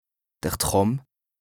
Français Dialectes du Bas-Rhin Dialectes du Haut-Rhin Page
2APRESTA_OLCA_LEXIQUE_INDISPENSABLE_BAS_RHIN_207_0.mp3